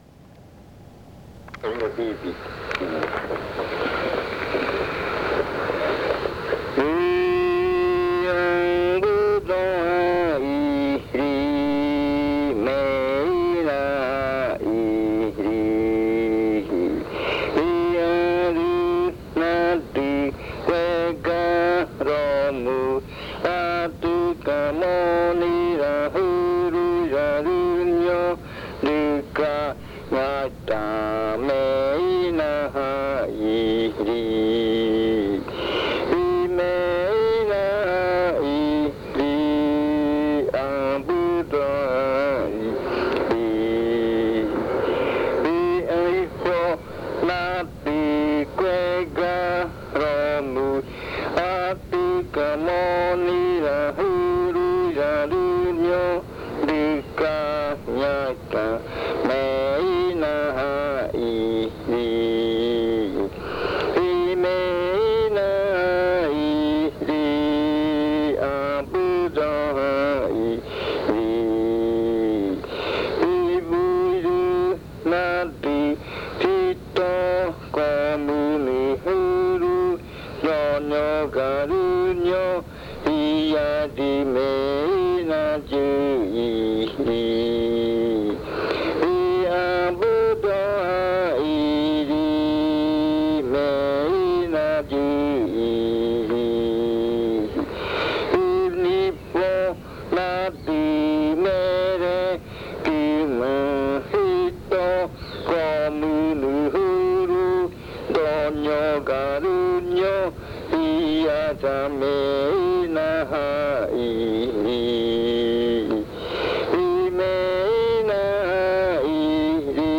Canto de madrugada (aiñora rua).
Early morning chant (aiñora rua).
with singer